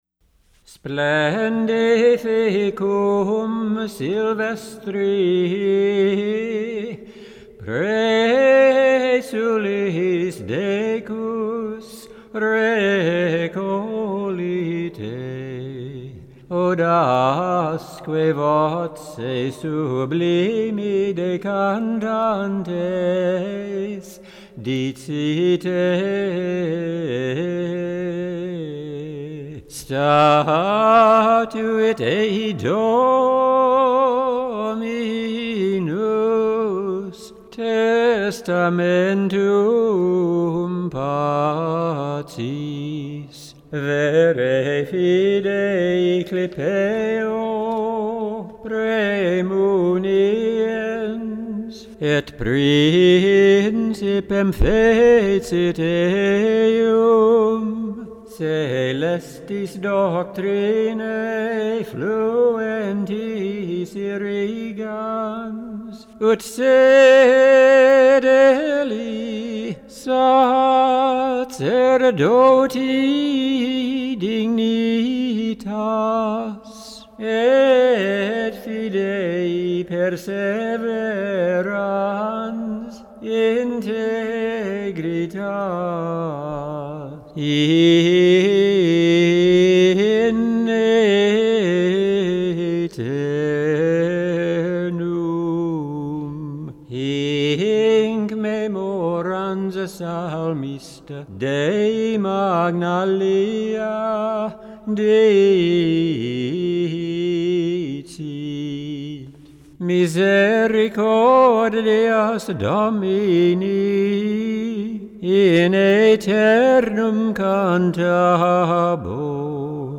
Medieval Chant